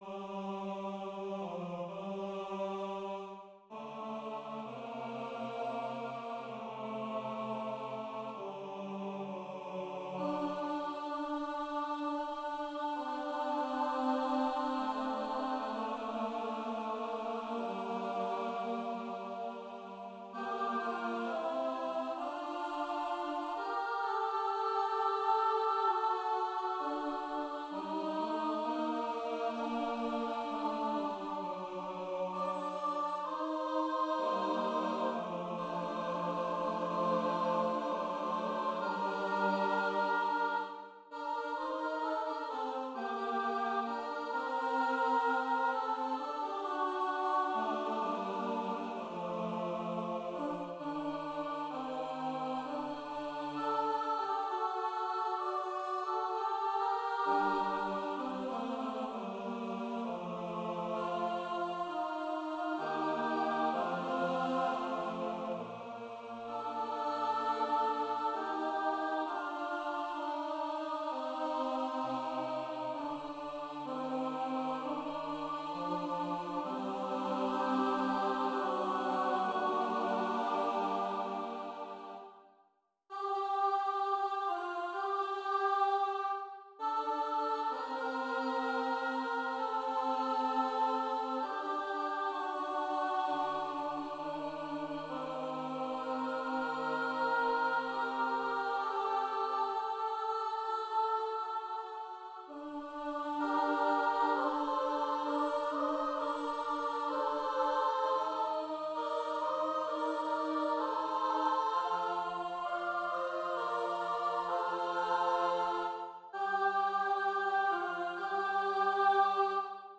SATB
Voicing/Instrumentation: SATB
His music blends early music, 20th-century elements, and fundamentalist musical traditions